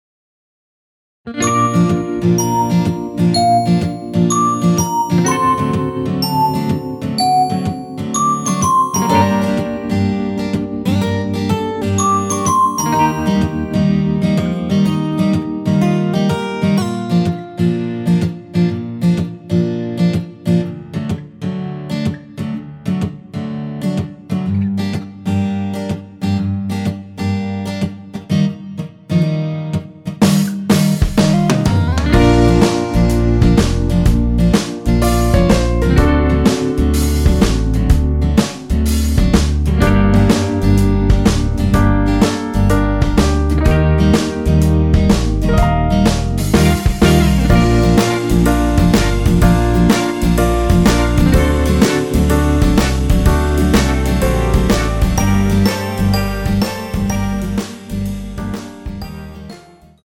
원키에서(-2)내린 MR입니다.
Bb
앞부분30초, 뒷부분30초씩 편집해서 올려 드리고 있습니다.
중간에 음이 끈어지고 다시 나오는 이유는